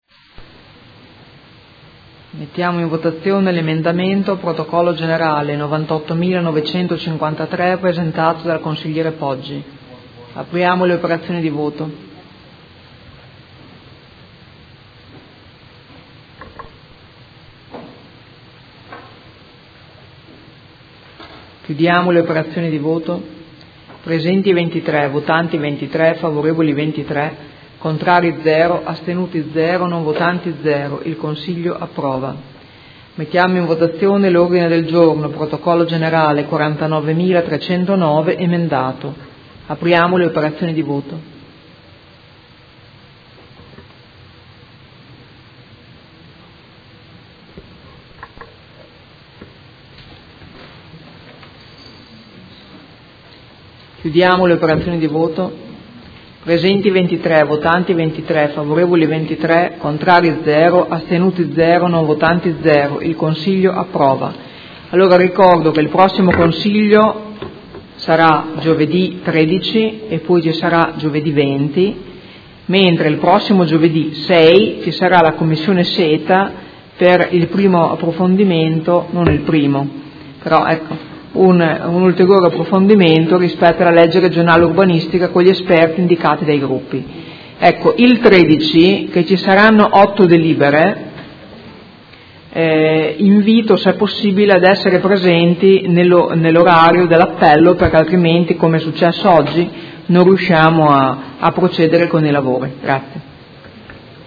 Seduta del 26/06/2017. Mette ai voti Ordine del Giorno presentato dai Consiglieri Pacchioni, Morini, Baracchi, Arletti, Di Padova, Liotti, Poggi, De Lillo, Venturelli (P.D.) e dal Consigliere Trande (Art.1-MPD) avente per oggetto: Cure palliative e terapia del dolore ed emendamento.